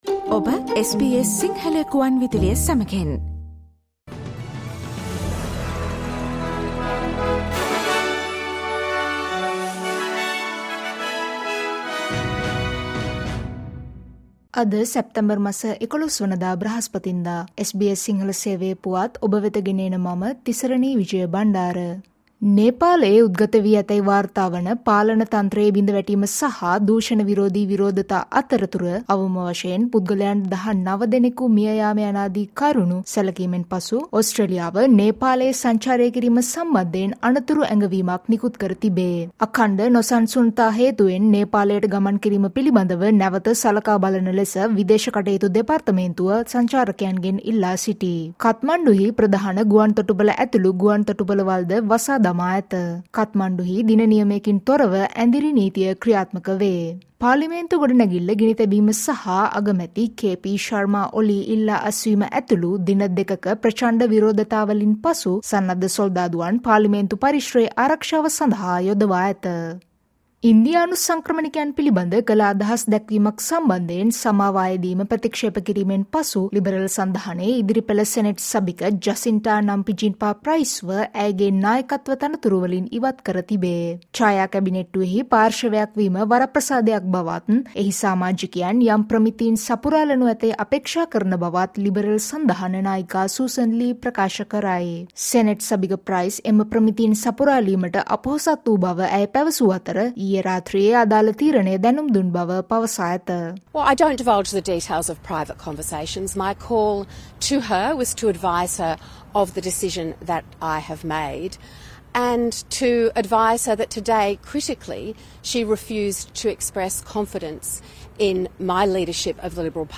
ඕස්ට්‍රේලියාවේ පුවත් සිංහලෙන් දැනගන්න, සැප්තැම්බර් මස 11වන දා SBS සිංහල Newsflashවලට සවන් දෙන්න